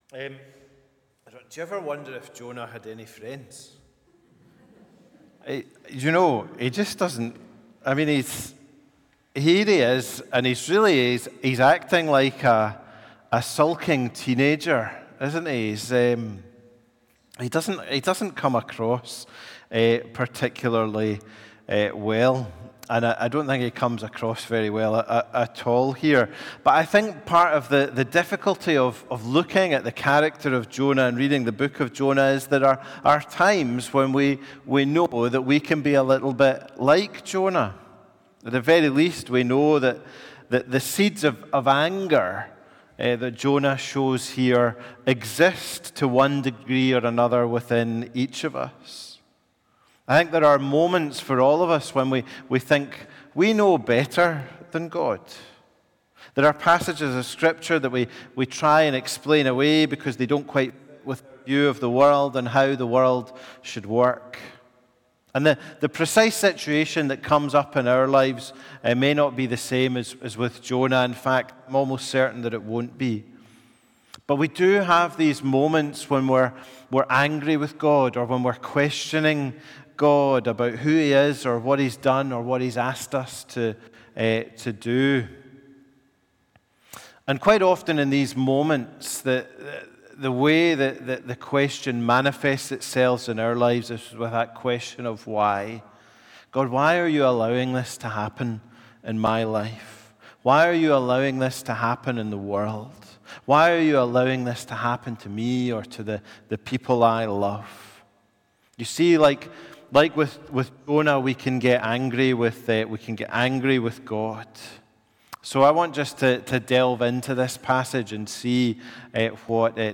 A Sermon from the series "Jonah."